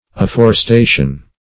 Afforestation \Af*for`es*ta"tion\, n.